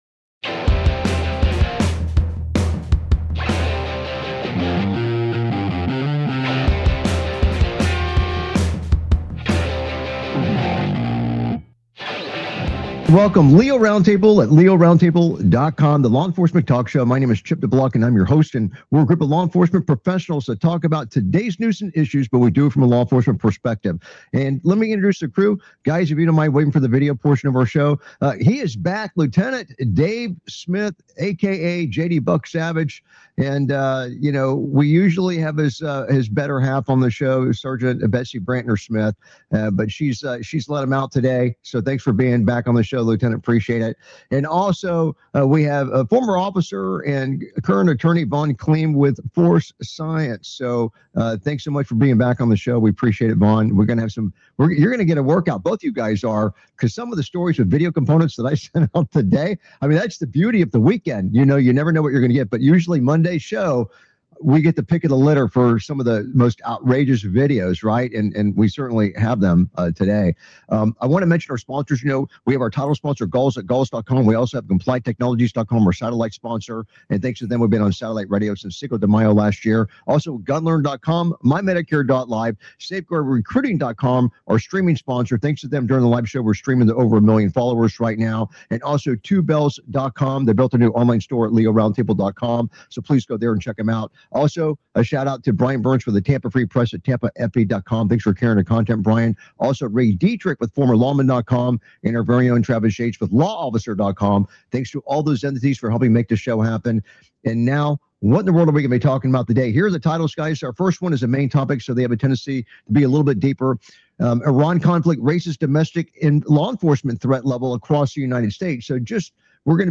Talk Show Episode, Audio Podcast, LEO Round Table and S11E063, Domestic Law Enforcement Threat Raised Nationwide Amid Iran Conflict on , show guests , about Domestic Law Enforcement Threat,S11E063 Domestic Law Enforcement Threat Raised Nationwide Amid Iran Conflict, categorized as Entertainment,Military,News,Politics & Government,National,World,Society and Culture,Technology,Theory & Conspiracy